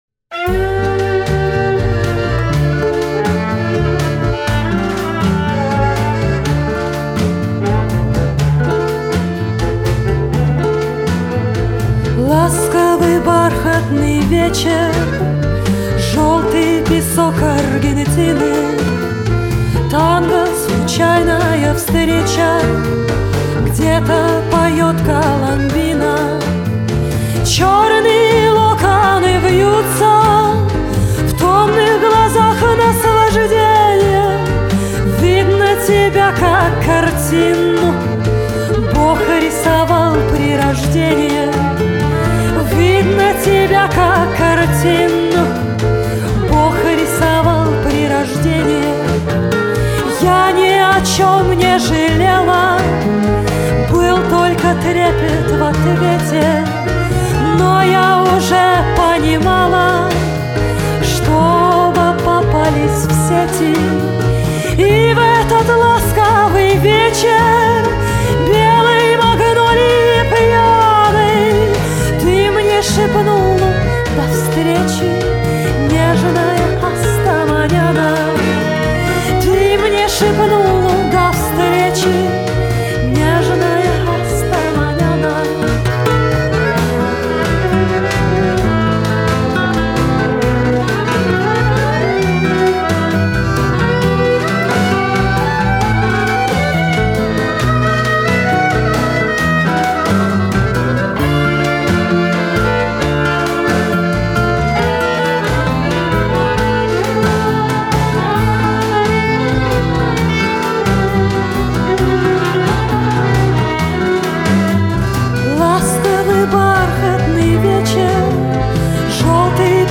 Genre: Шансон